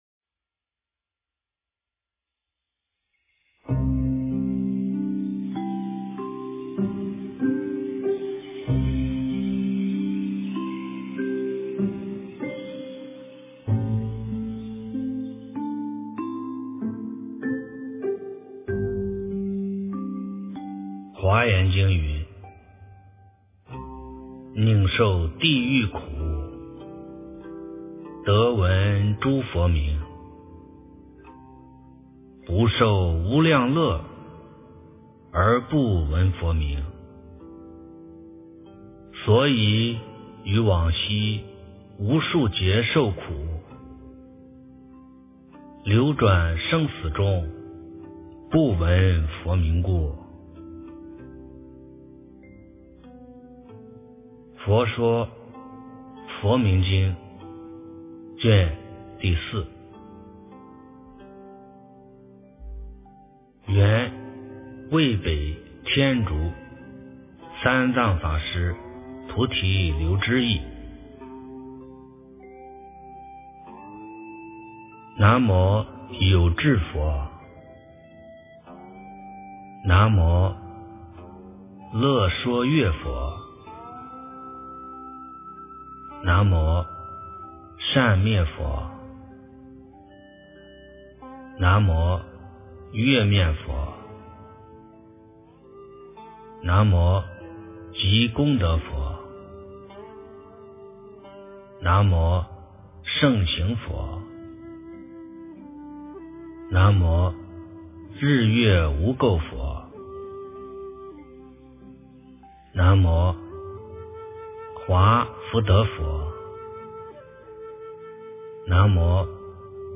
万佛名经第04卷 - 诵经 - 云佛论坛